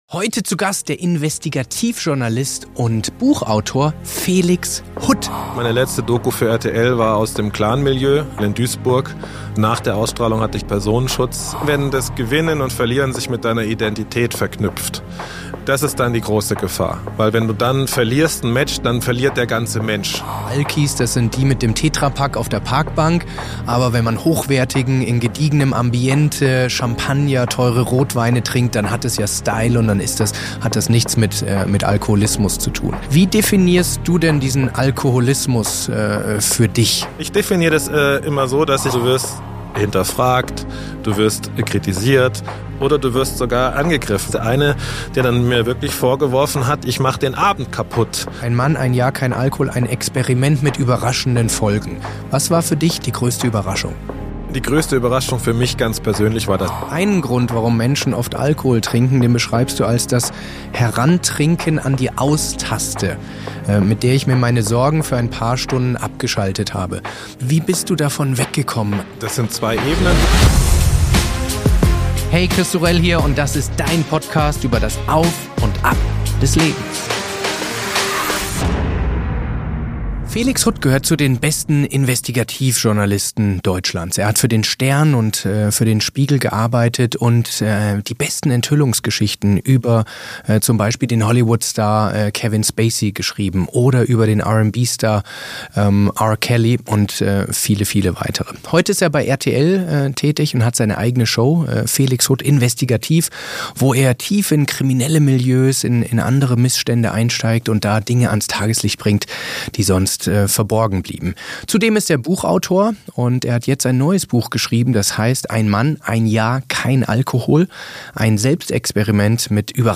Dieses Gespräch ist KEIN Moralplädoyer gegen Alkohol, sondern ein sehr reflektierter Einblick in ein spannendes Sozialexperiment, in dem sehr viele Menschen sich in der ein oder anderen Rolle ganz sicher wiederfinden werden...